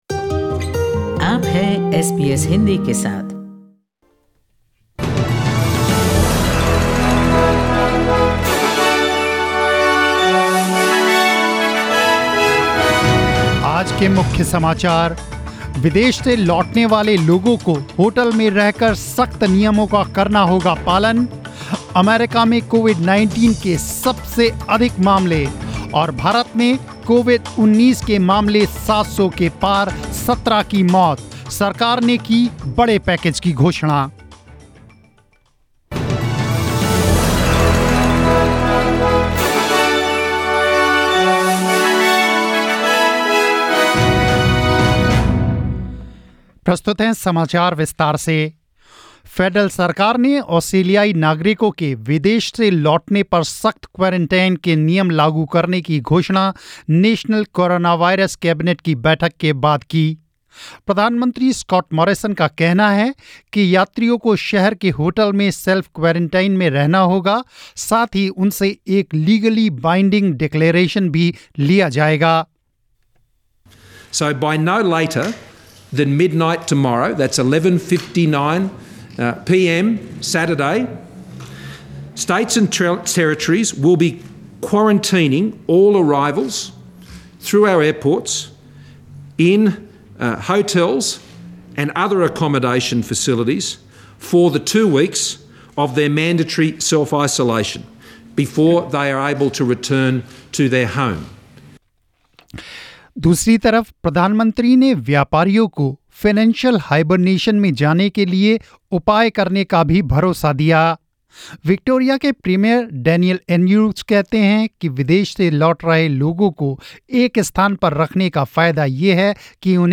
News in Hindi 27 March 2020